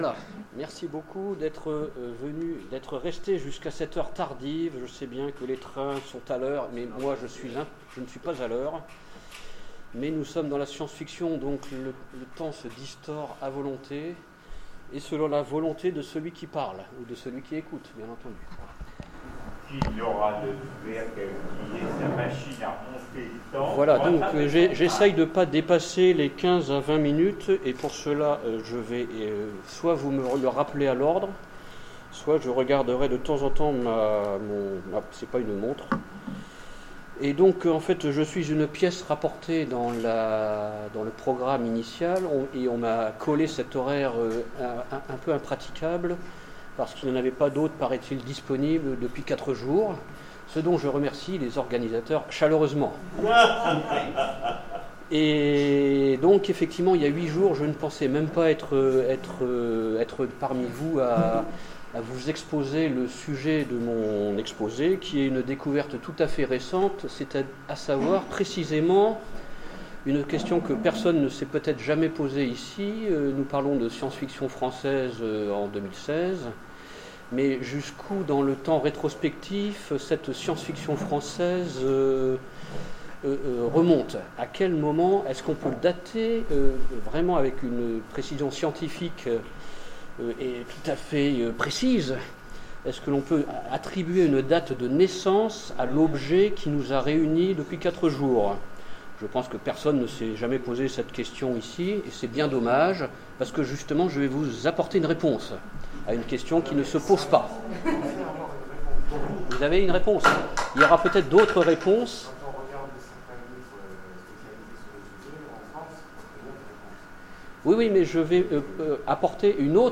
Convention SF 2016 : Conférence La SF avant 1850